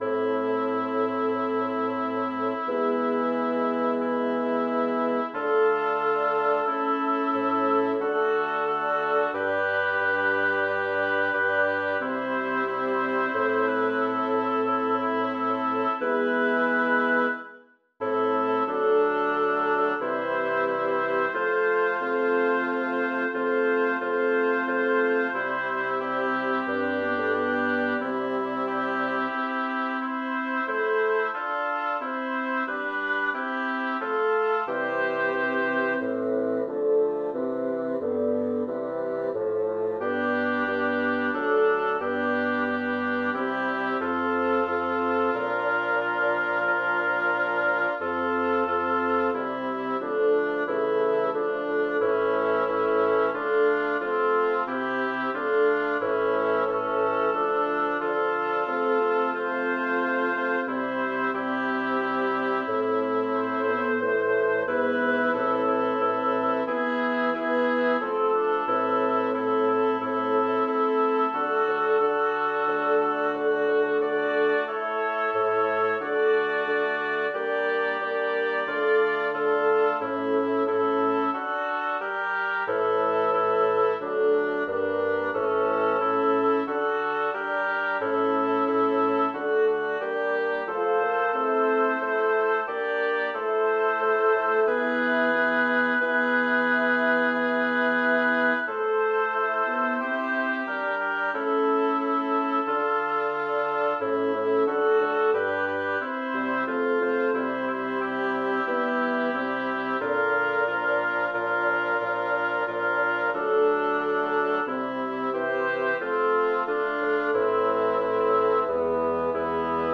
Title: Memento Domine David Composer: Jacob Meiland Lyricist: Number of voices: 5vv Voicing: SATTB Genre: Sacred, Motet
Language: Latin Instruments: A cappella